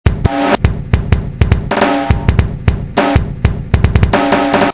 さらにRenoiseのCompressorとローファイ系のVSTを掛けました。
最初のサンプルを駆使した新たなフレーズ・サンプル（MP3）